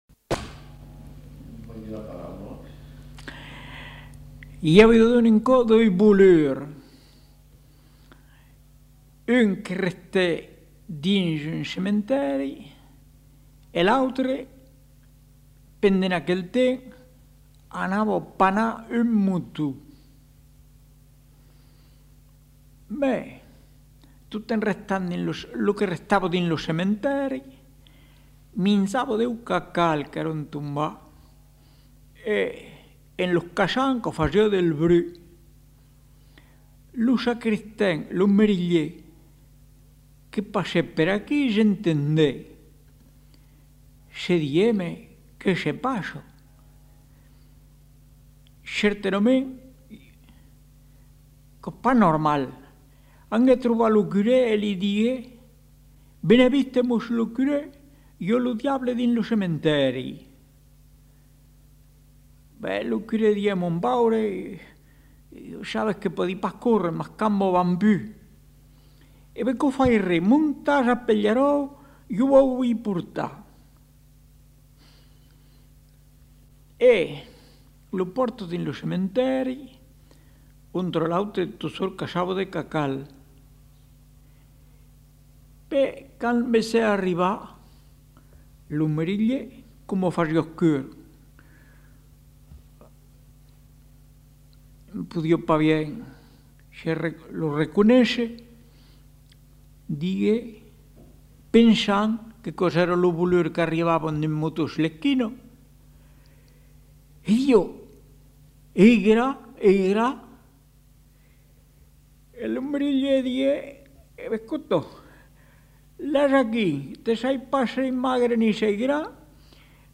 Aire culturelle : Périgord
Genre : conte-légende-récit
Effectif : 1
Type de voix : voix d'homme
Production du son : parlé